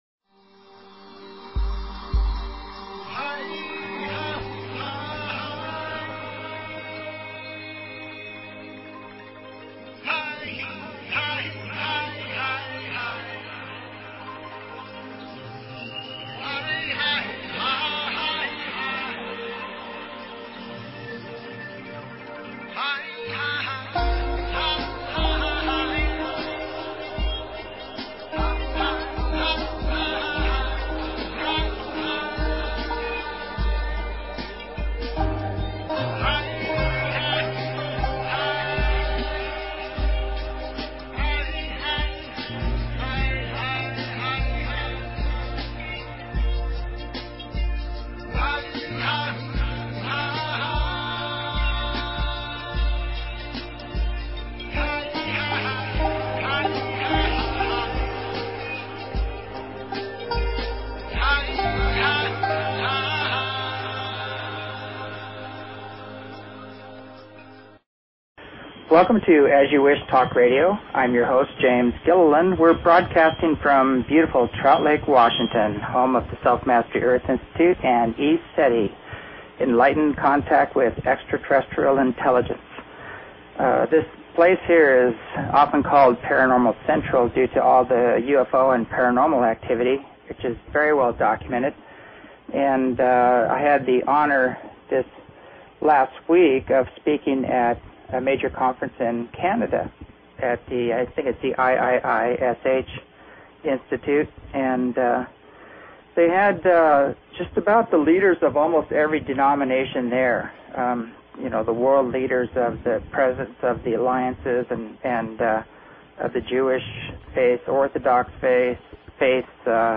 Talk Show Episode, Audio Podcast, As_You_Wish_Talk_Radio and Courtesy of BBS Radio on , show guests , about , categorized as
As you Wish Talk Radio, cutting edge authors, healers & scientists broadcasted Live from the ECETI ranch, an internationally known UFO & Paranormal hot spot.